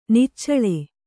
♪ niccaḷe